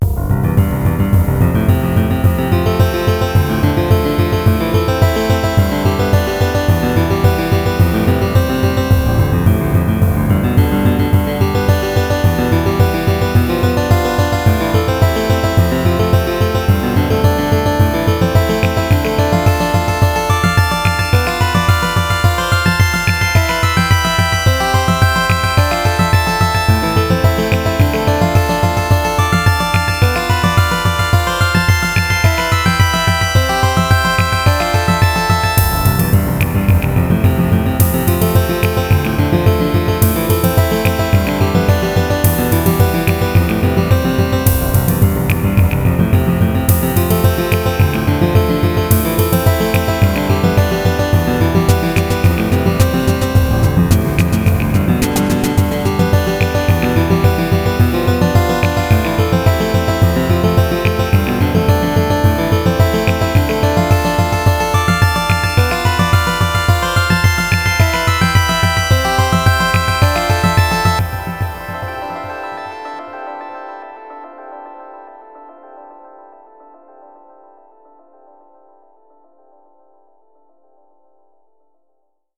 Genre Experimental